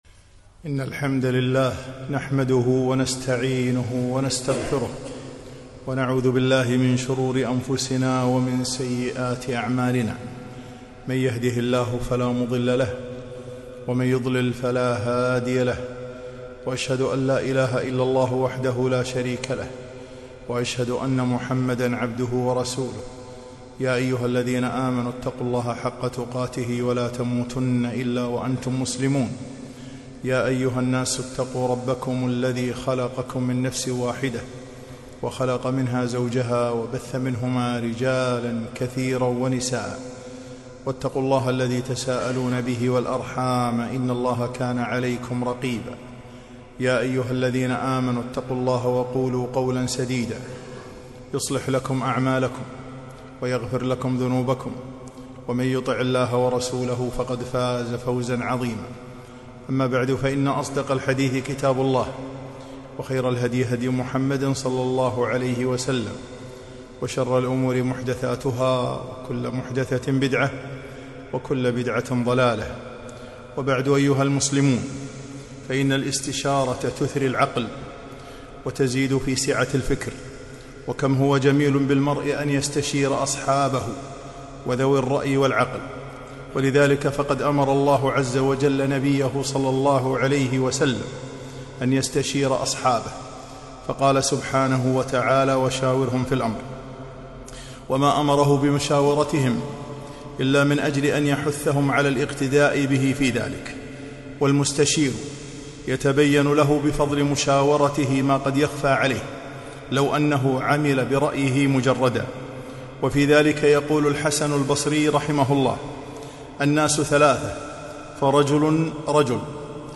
خطبة - فضائل الاستشارة